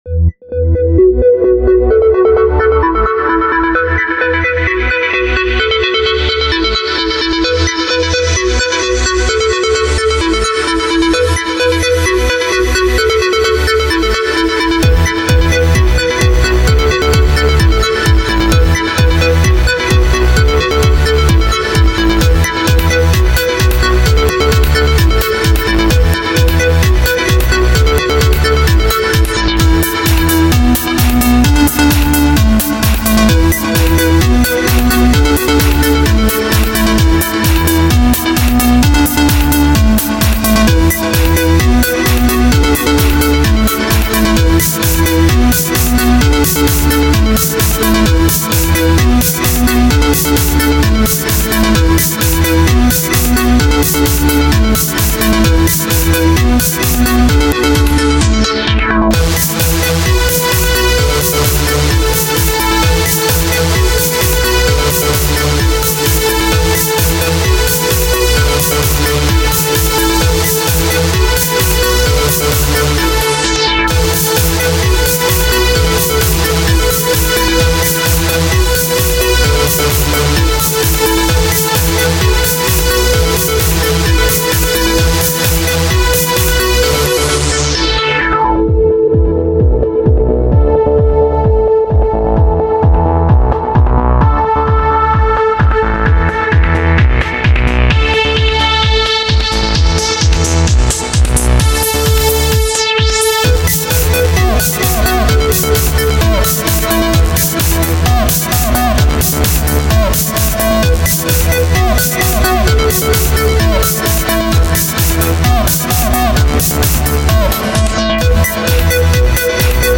Скачать Минус
Стиль: Dance (Club)